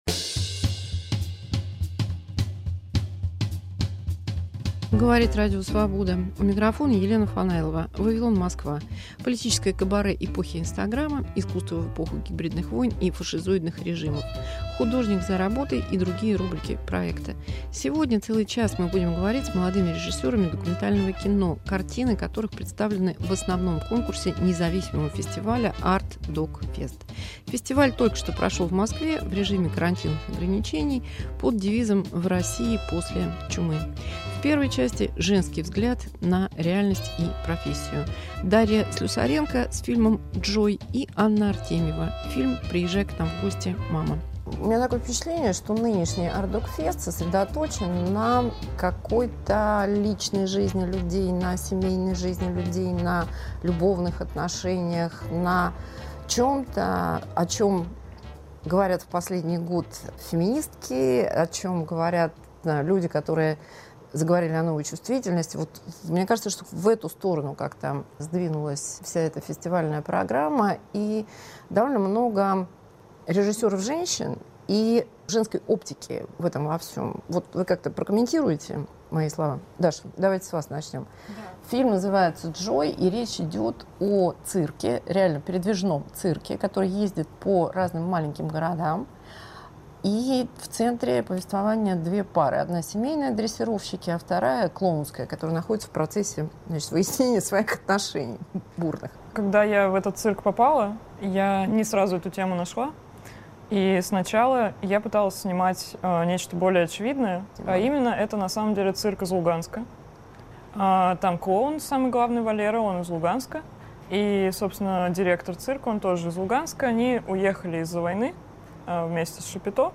Женский взгляд на кино. Разговор с молодыми режиссёрами